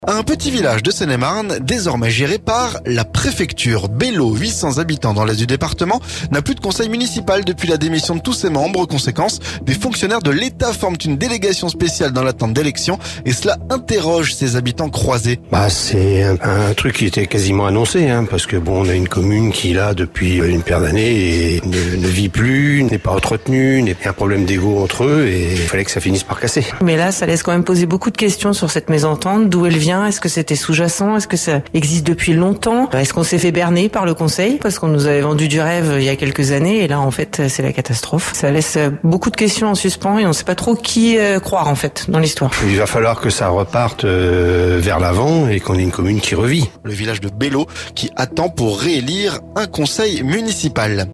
Bellot, 800 habitants dans l'est du département, n'a plus de conseil municipal depuis la démission de tous ses membres. Conséquence : des fonctionnaires de l'Etat forment une délégation spéciale dans l'attente d'élections. Et cela interroge ces habitants croisés.